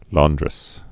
(lôndrĭs, län-)